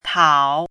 chinese-voice - 汉字语音库
tao3.mp3